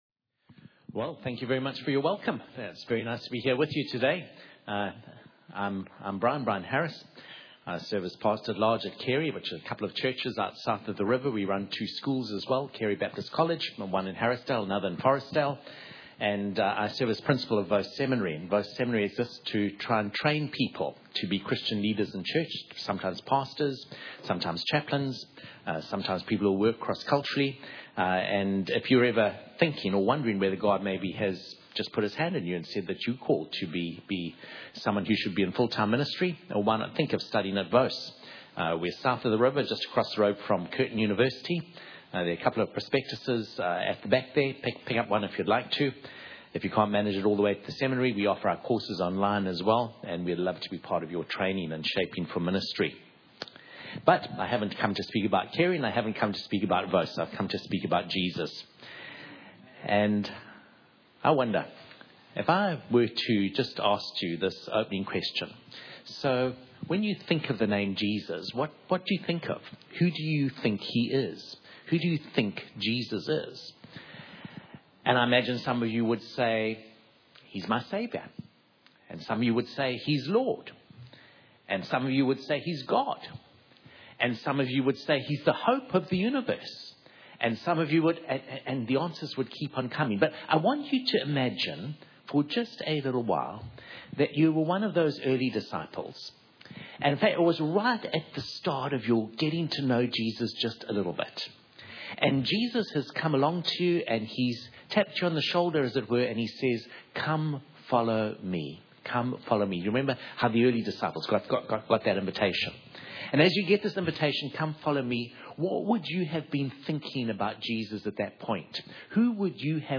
Guest Speaker Service Type: Sunday Morning « Favour from the Lord Revival P4